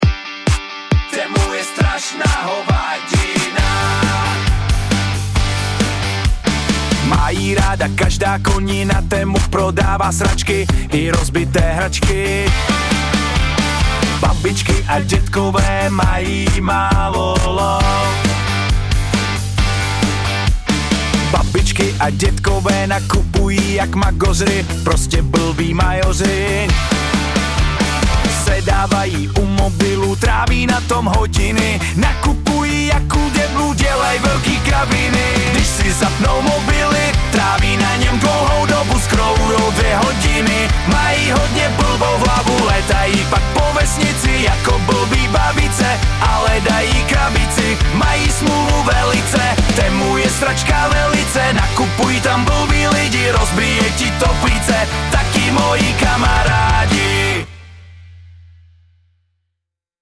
Collaborative lyric writing with short, punchy hooks.
Generated track
Ready-to-play MP3 from ElevenLabs Music.